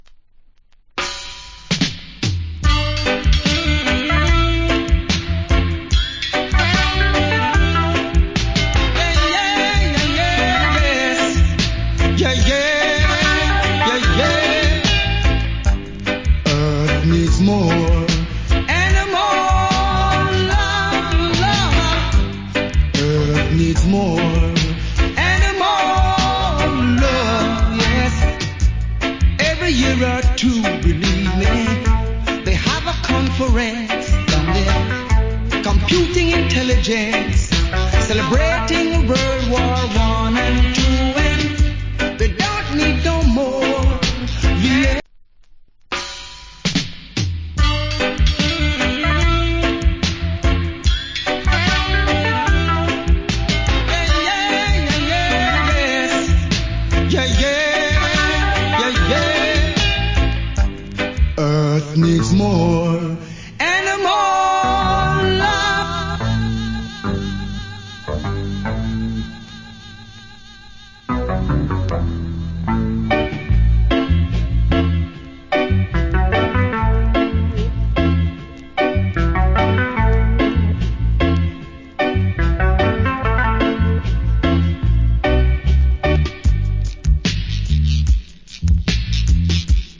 コメント 80's Cool Reggae Vocal. / Nice Dub.